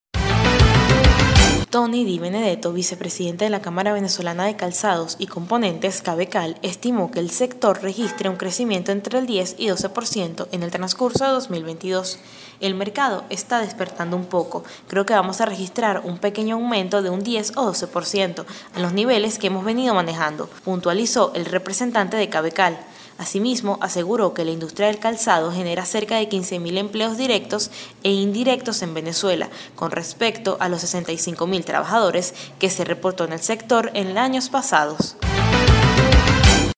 Cavecal estima un crecimiento entre el 10 y 12% en 2022 (Audio Noticia)